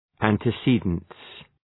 Προφορά
{,æntı’si:dənts}